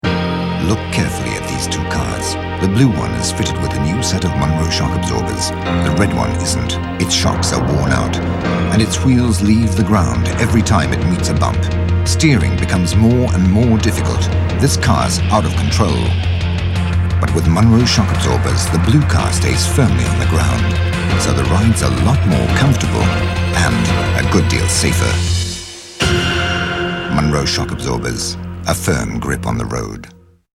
Well, I'd say it's deep, warm, persuasive, with sincerity and authority - but why not have a listen and judge for yourself?
Sprecher englisch / britisch.
Sprechprobe: Industrie (Muttersprache):